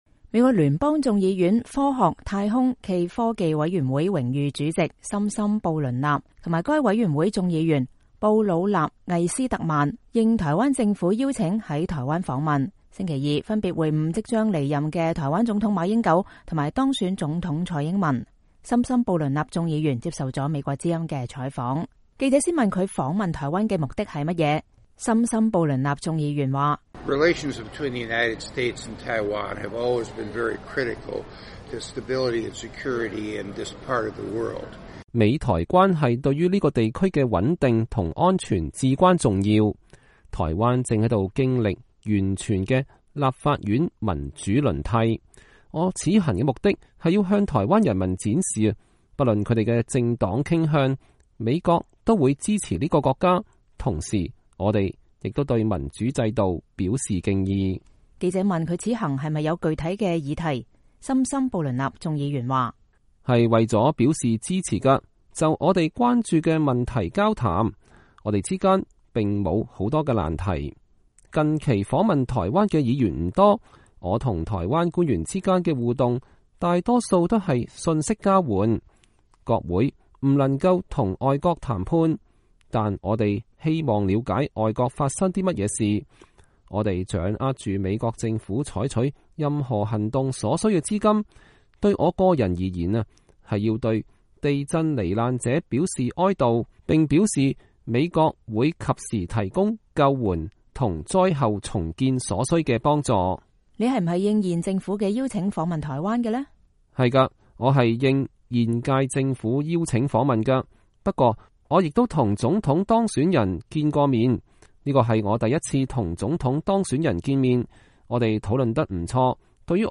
森森布倫納眾議員接受了美國之音的採訪。